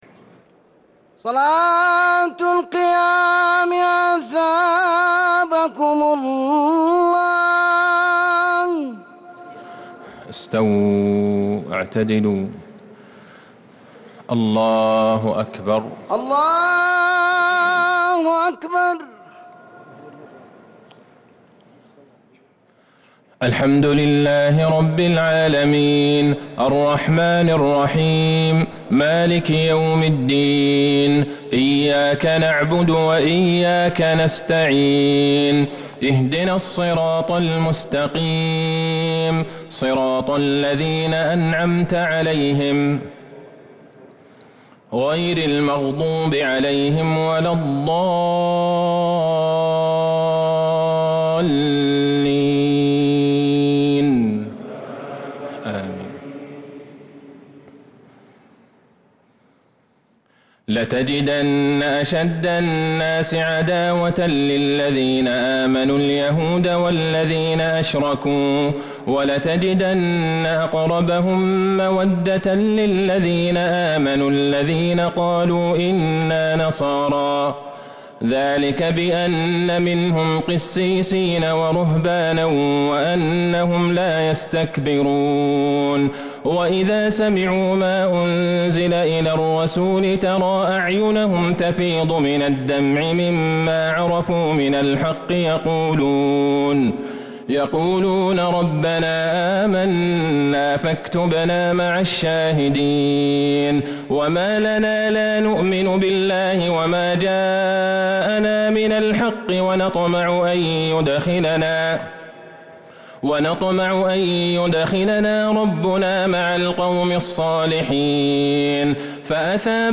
تراويح ليلة ٩ رمضان ١٤٤٣هـ | آخر المائدة وأول الأنعام | taraweeh prayer from Surah Al-maedah & Al-An’aam 9-4-2022 > تراويح الحرم النبوي عام 1443 🕌 > التراويح - تلاوات الحرمين